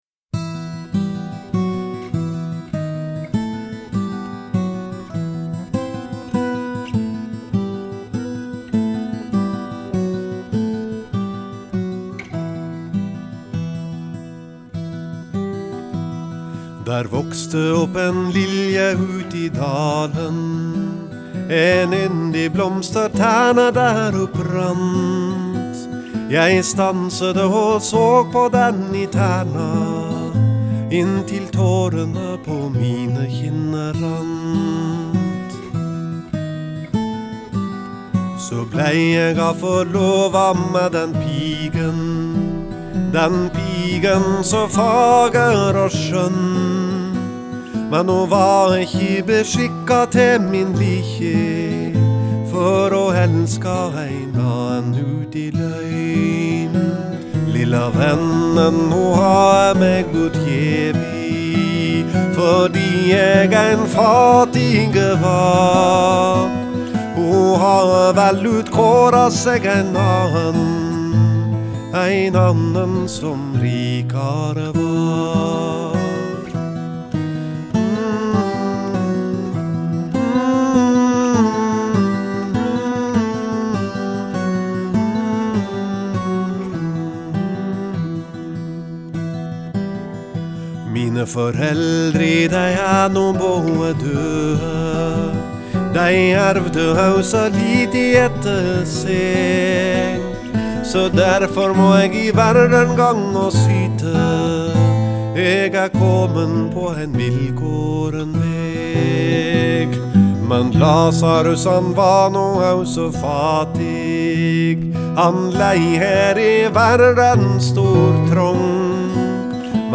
Genre: Folk-Rock, Folk-Pop, Folk
vocals, Guitar, banjo, harmonica, percussion, accordion